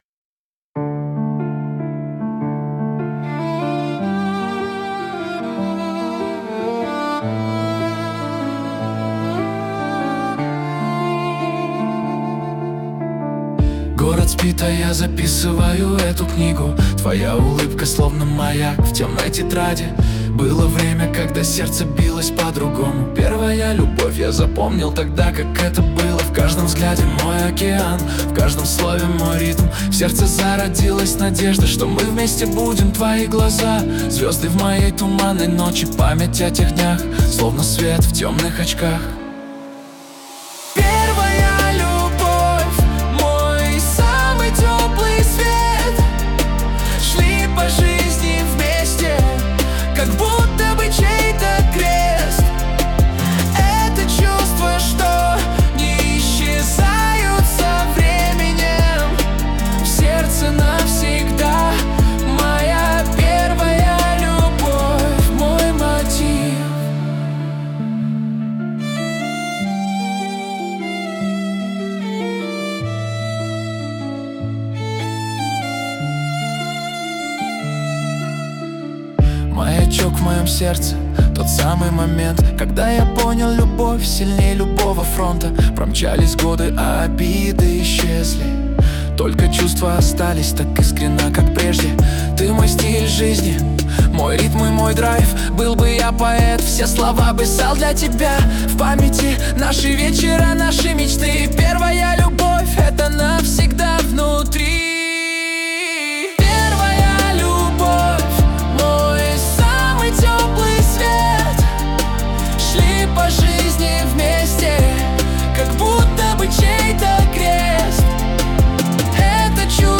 Песня про первую любовь в стиле рэп